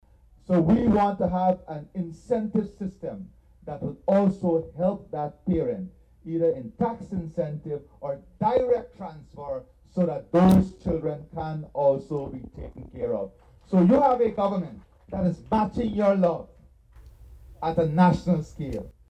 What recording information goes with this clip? The Head of State made this announcement while addressing residents during his trip to Region Six.